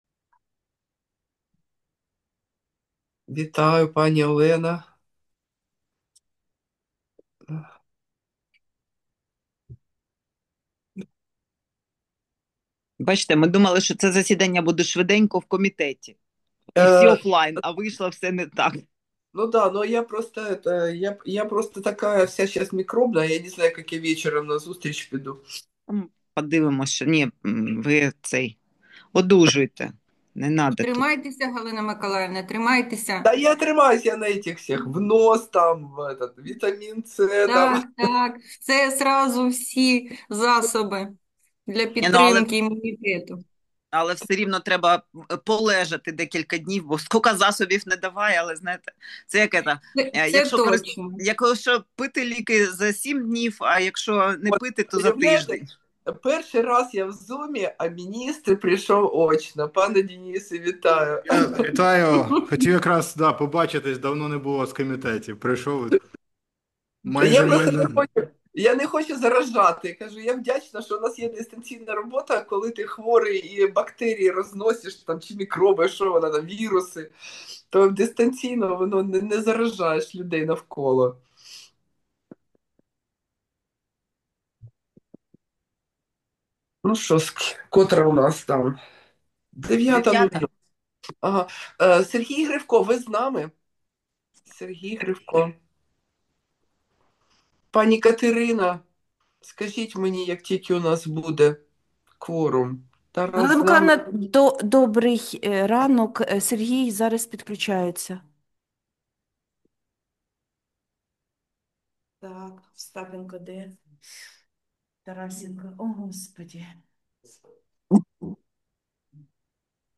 Засідання Комітету від 20 листопада 2025 року